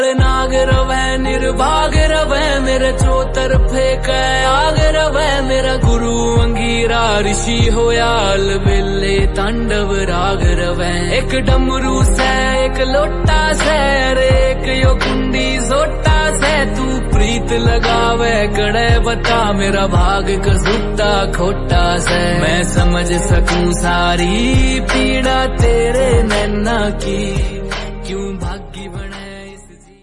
Category: Bhakti Ringtones